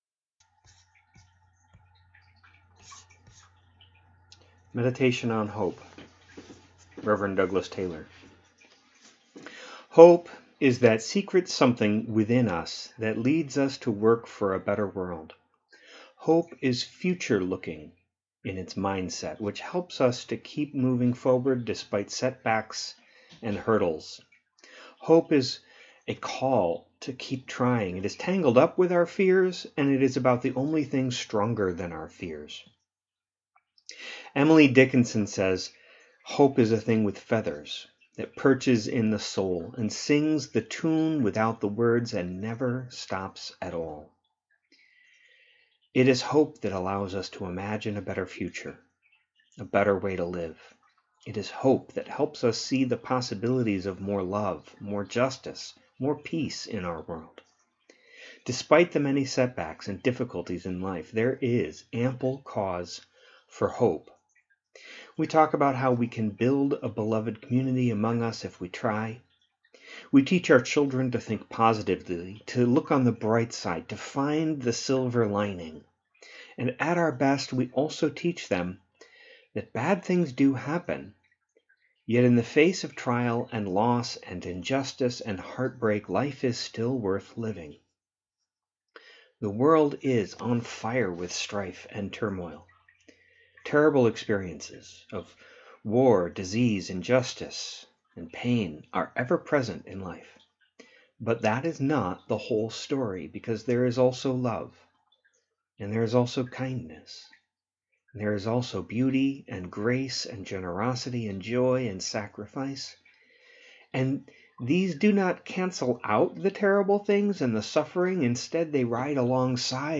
Meditation on Hope